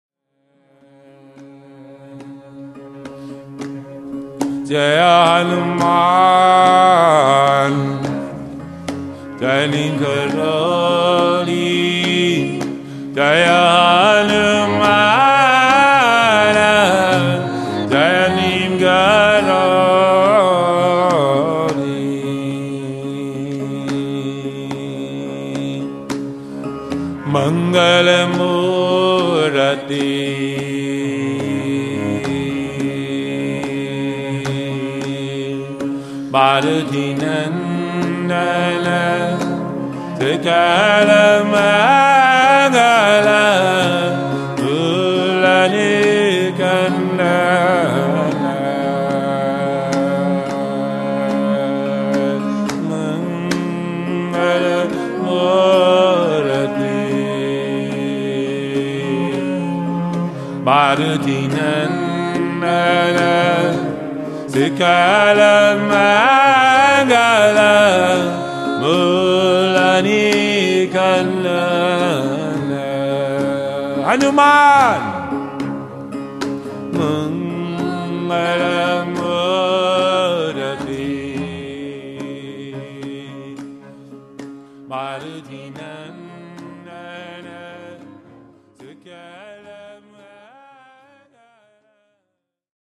Live Kirtan Chanting CD